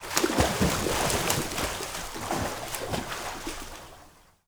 SPLASH_Movement_04_mono.wav